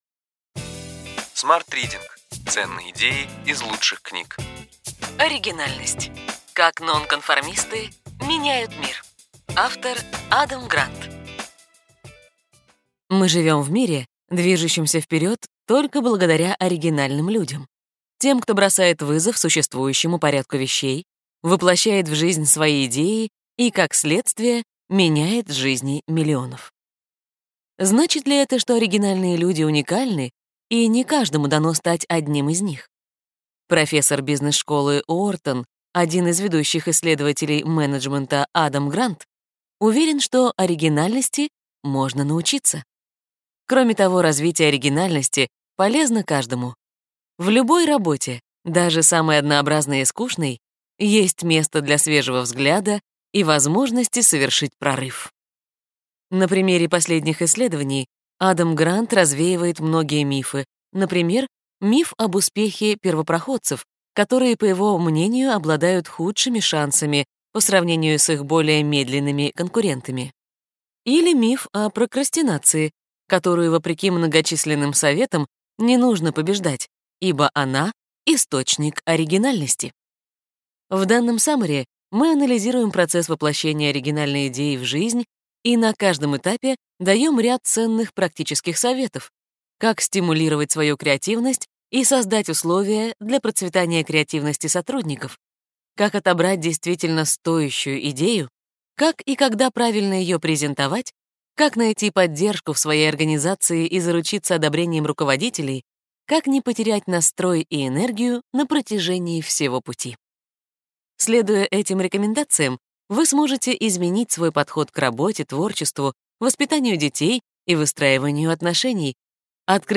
Аудиокнига Ключевые идеи книги: Оригинальность: как нонконформисты меняют мир.